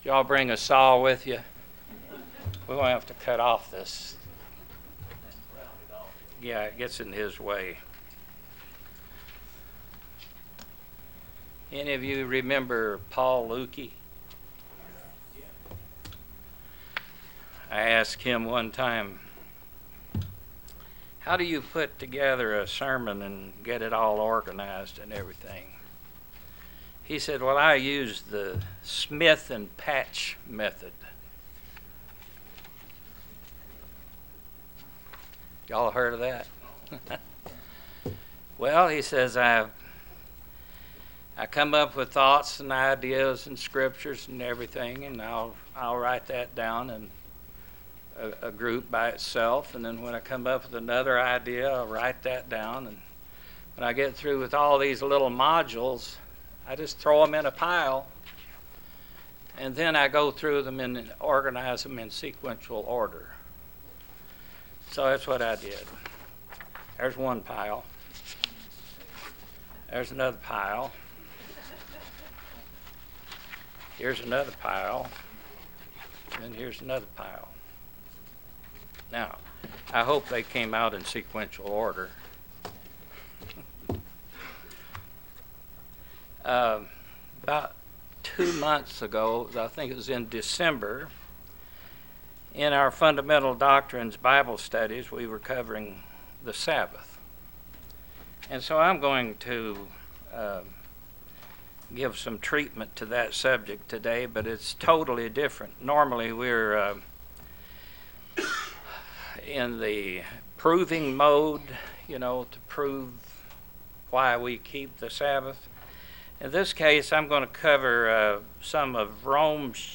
Sermons
Given in Knoxville, TN London, KY